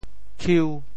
“裘”字用潮州话怎么说？
裘 部首拼音 部首 衣 总笔划 13 部外笔划 7 普通话 qiú 潮州发音 潮州 kiu5 文 中文解释 求 <名> (《说文》以“求”为“裘”之古文。
khiu5.mp3